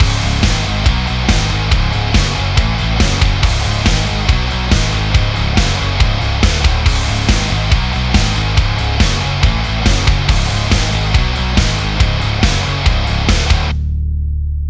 Рокешничек, проба пера с новыми мониторами
Не сильно ли пережато? Хотелось больше кача добавить миксу.